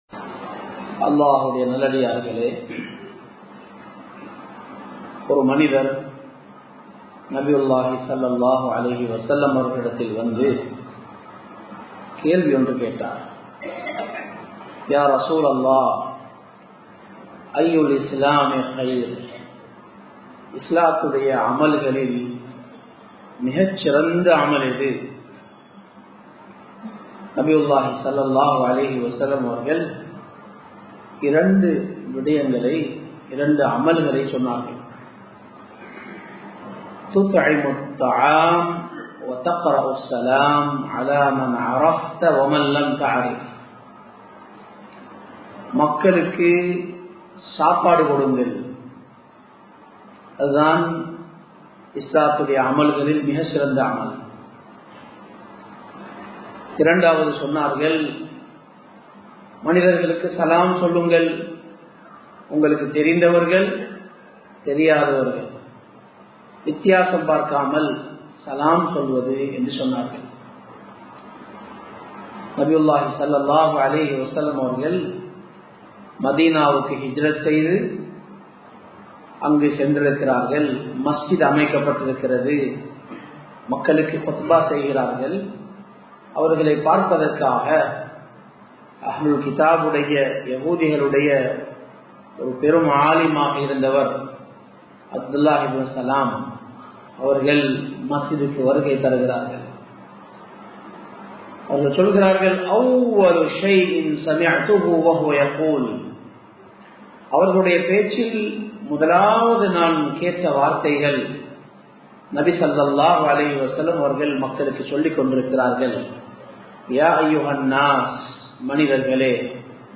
மற்றவர்களுக்கு உதவுவோம் | Audio Bayans | All Ceylon Muslim Youth Community | Addalaichenai
Samman Kottu Jumua Masjith (Red Masjith)